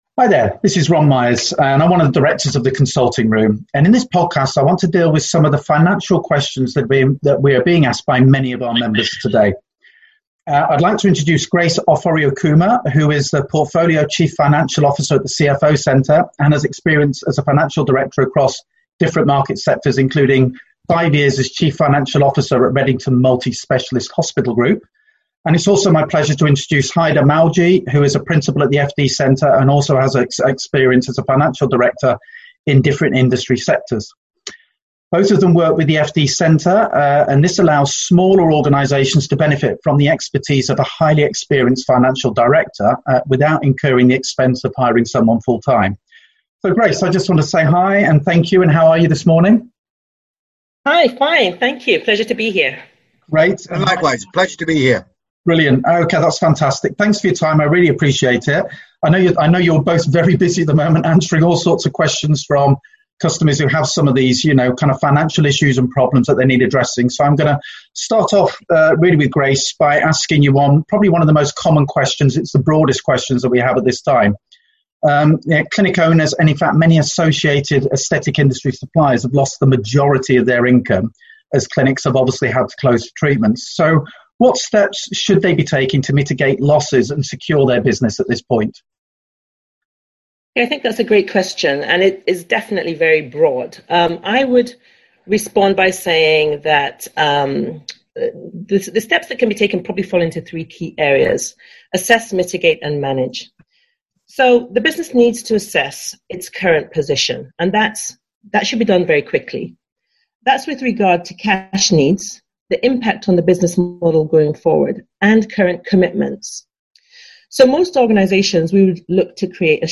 The Consulting Room Group interviewed two Financial Directors from the FD Centre to answer some of the common financial questions that our Members have.